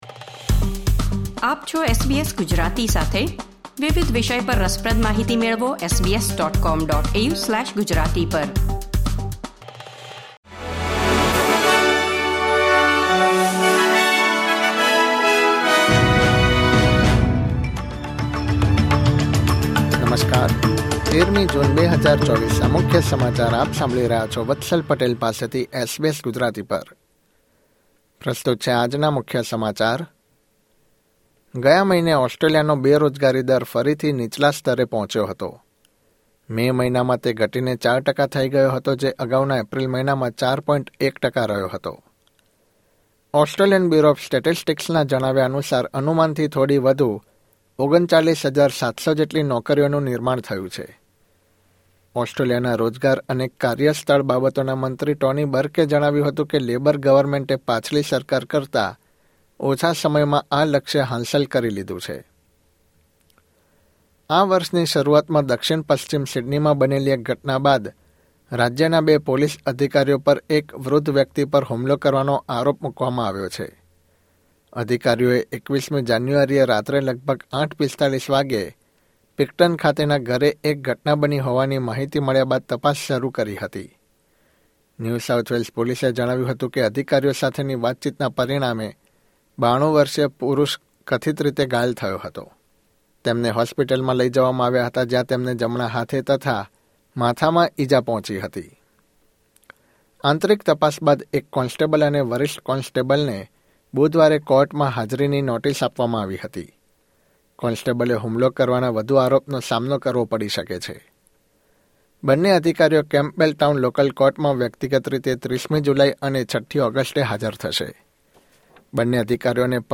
SBS Gujarati News Bulletin 13 June 2024